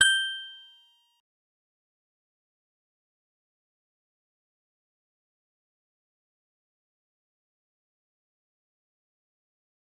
G_Musicbox-G7-mf.wav